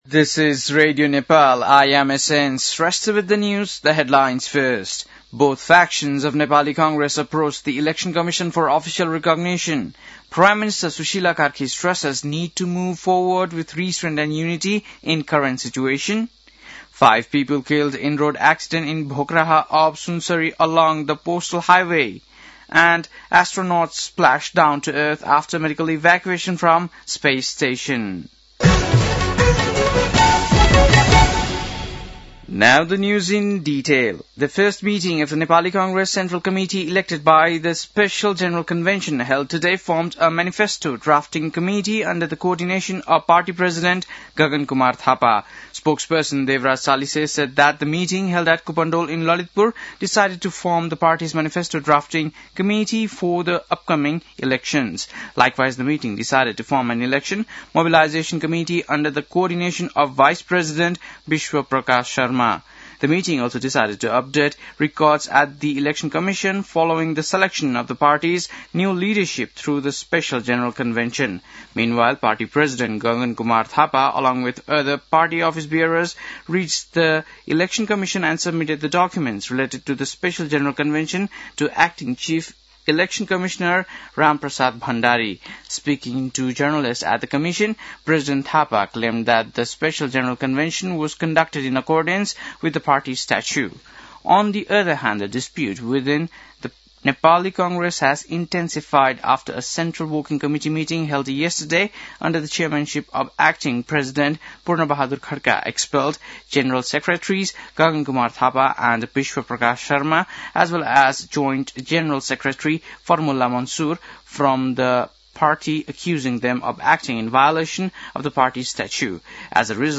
बेलुकी ८ बजेको अङ्ग्रेजी समाचार : १ माघ , २०८२
8-pm-english-news-.mp3